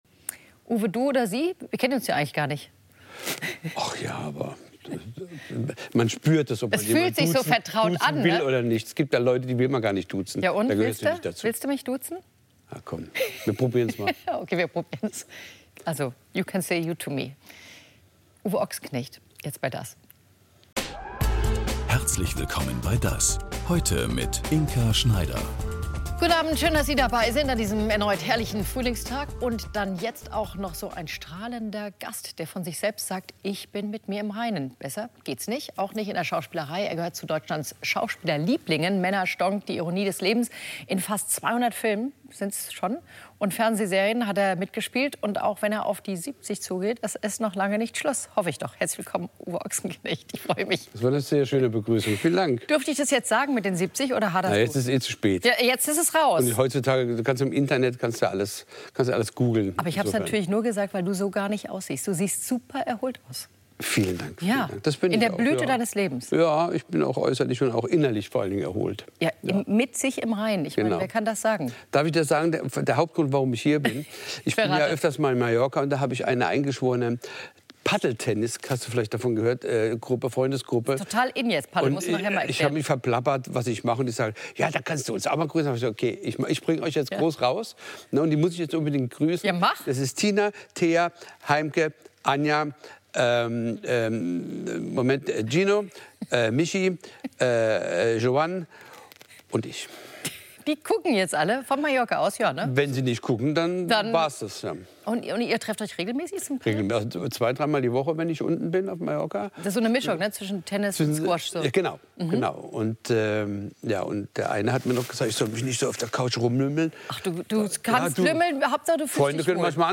DAS! - täglich ein Interview